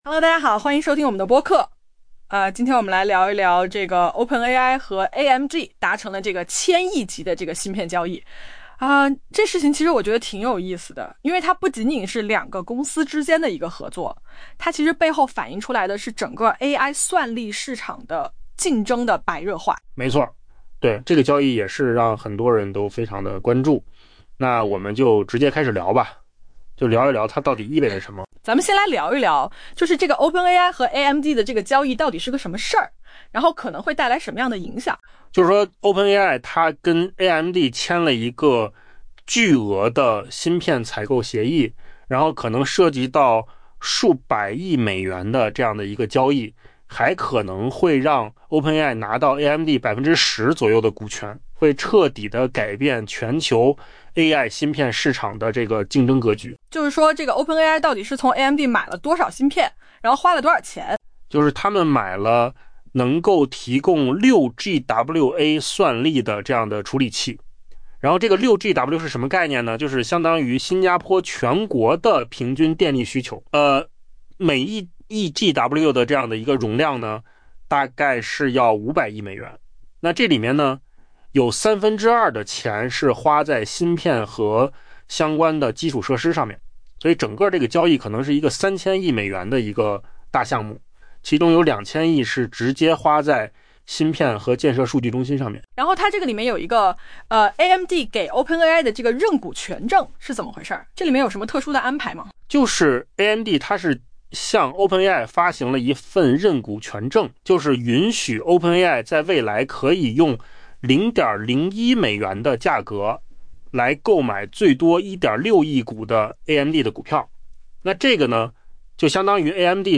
AI 播客：换个方式听新闻 下载 mp3 音频由扣子空间生成 OpenAI 已同意购买价值数百亿美元的 AMD 芯片 ，作为交易的一部分，这家 ChatGPT 的缔造者最终可能 获得这家市值 2700 亿美元的芯片制造商约 10% 的股权。